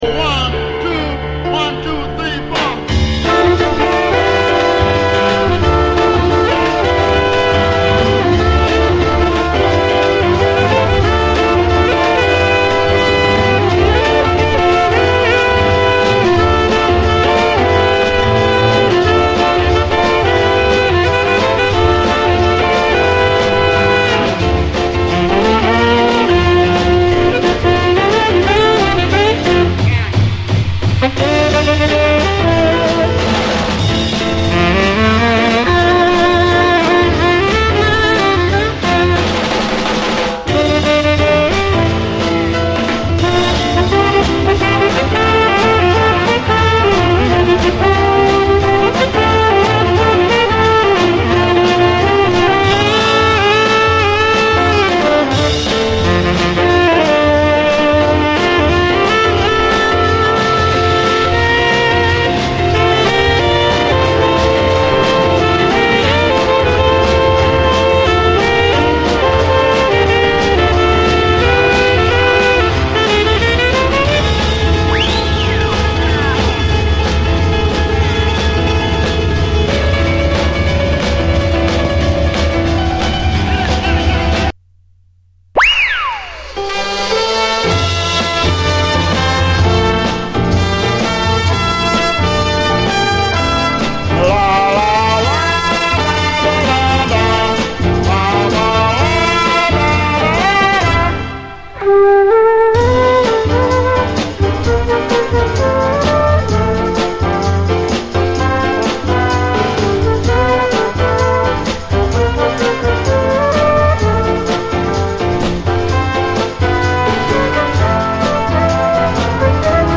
ビート感といい、とにかくファット！
ジャズ・ファンク